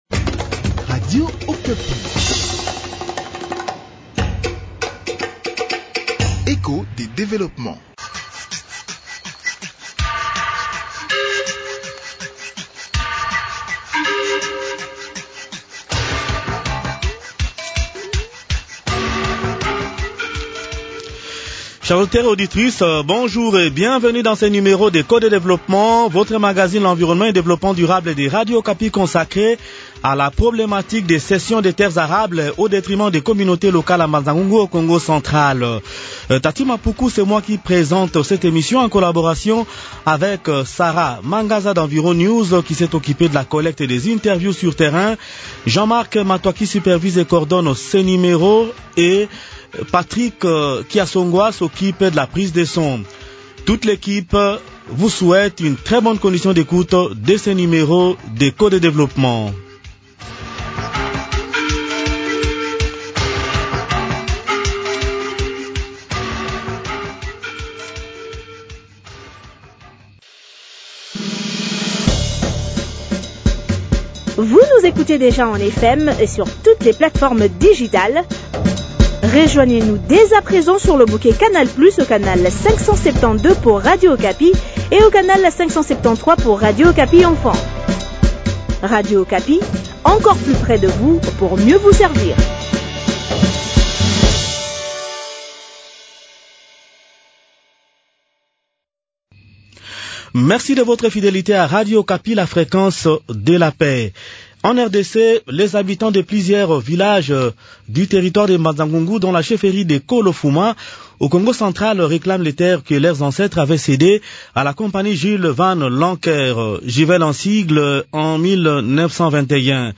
Ce grand reportage est réalisé en co-production avec Environews sur appui financier de Rainforest Journalist Fund (RJF).